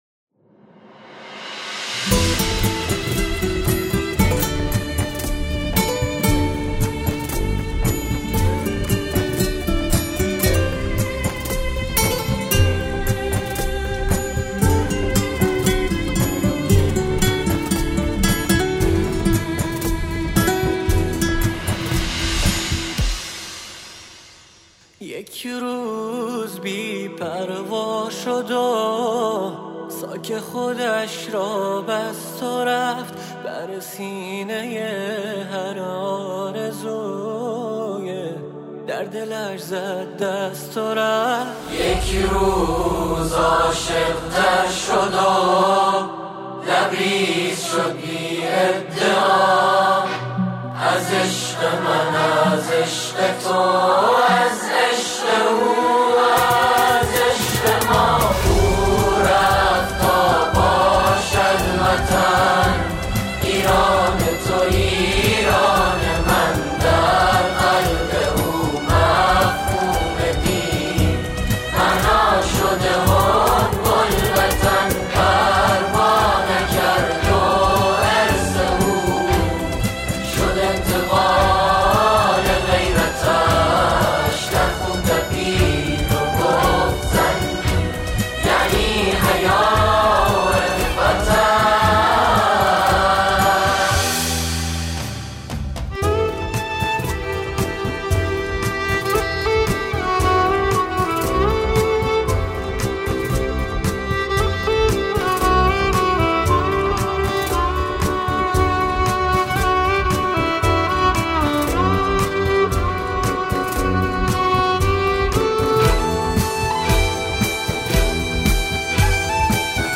نماهنگ زیبای دفاع مقدس